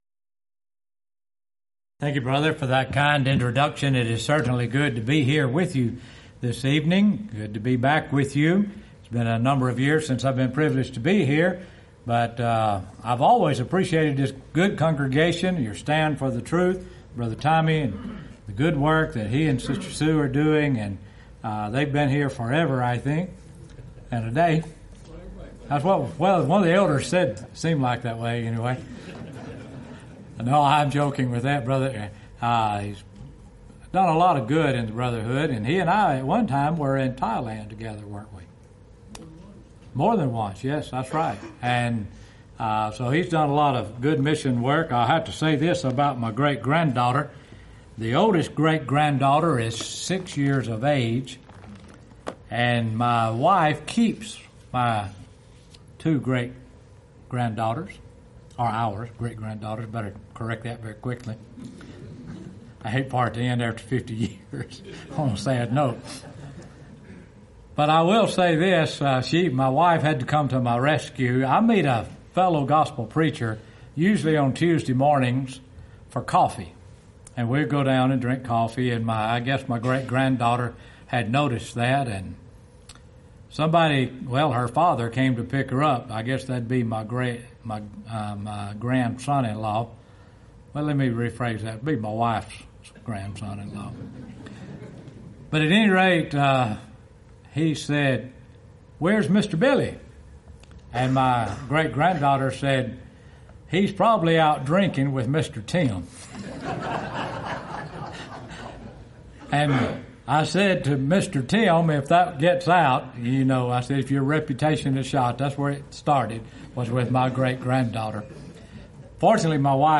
Event: 26th Annual Lubbock Lectures Theme/Title: God is Love